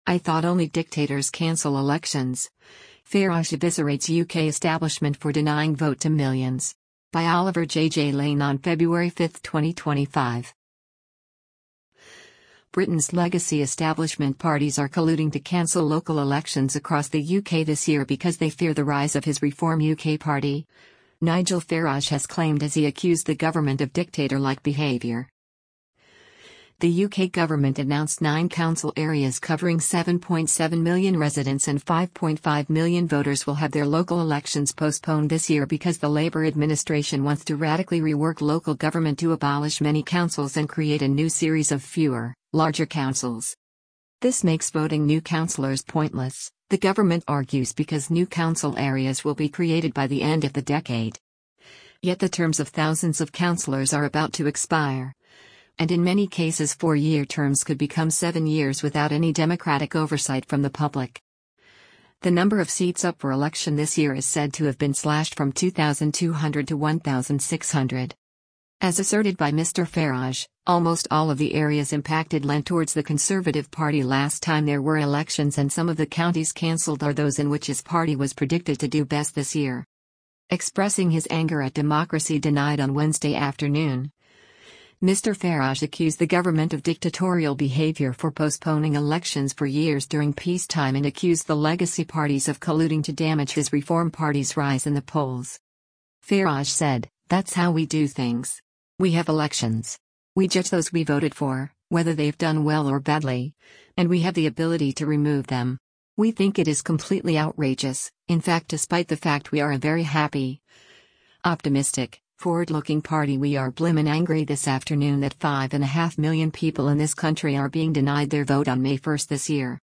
Reform UK leader Nigel Farage speaking during a press conference at Church House in centra